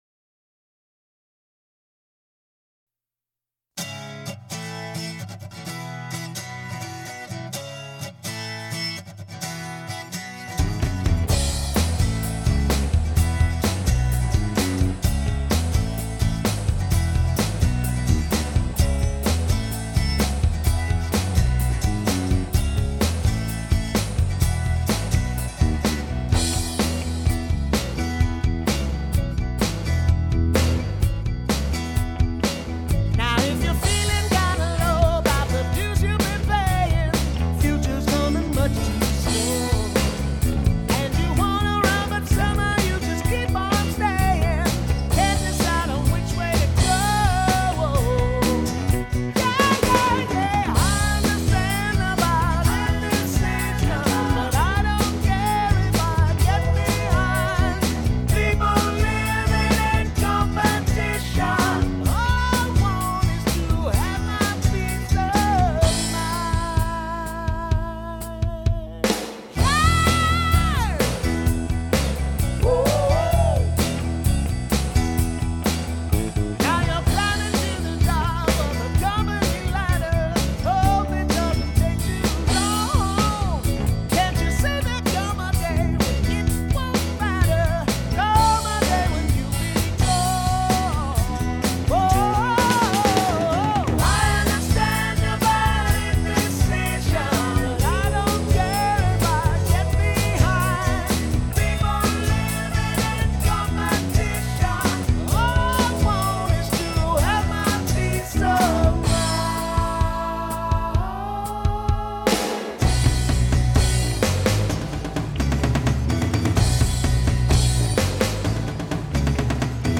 This is what I started with and then added my guitar parts
I purchased a backing track that had Drums, Bass and vocals.